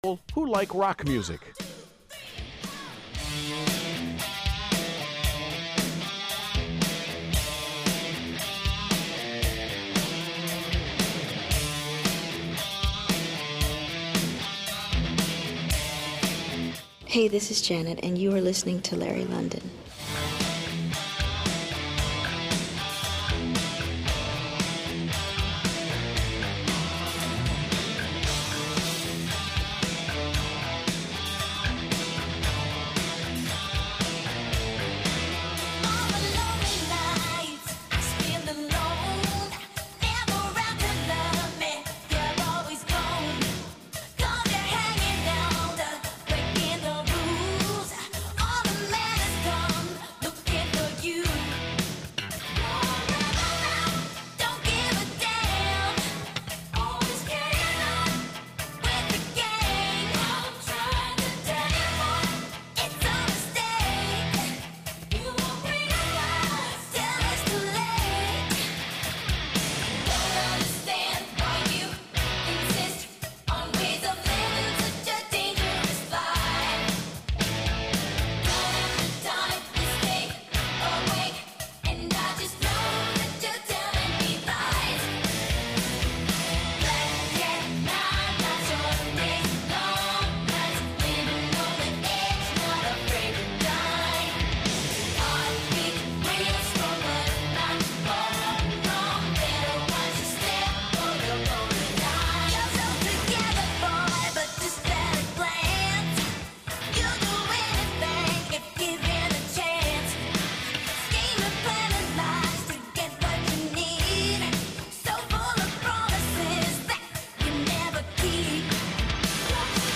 Cápsulas Informativas